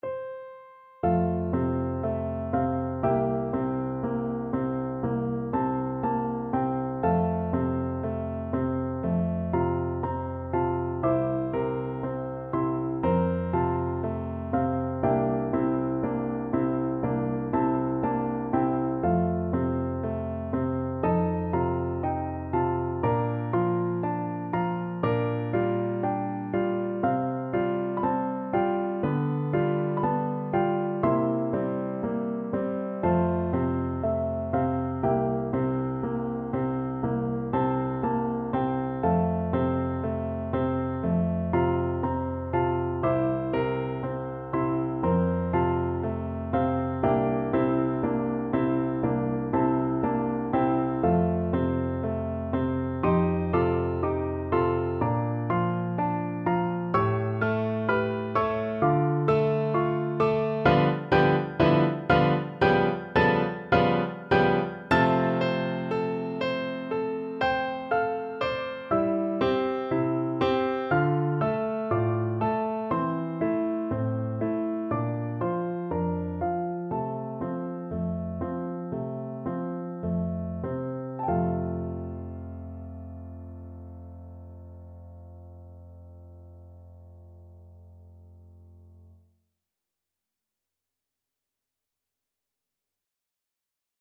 Free Sheet music for Piano Four Hands (Piano Duet)
2/4 (View more 2/4 Music)
Lento ma non troppo = c. 60
Classical (View more Classical Piano Duet Music)